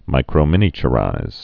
(mīkrō-mĭnē-chə-rīz, -mĭnə-)